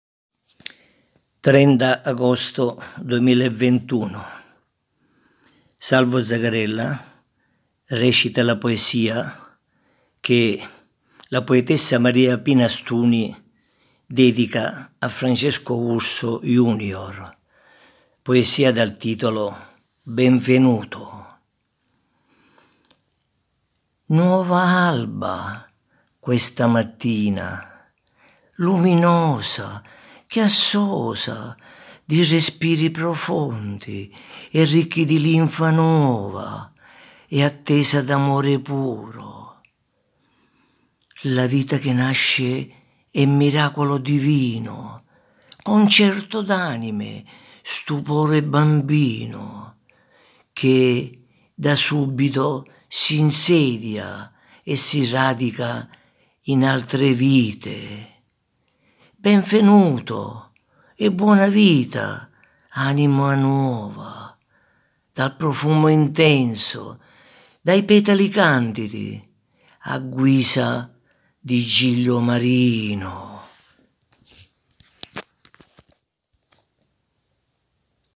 interpreta la poesia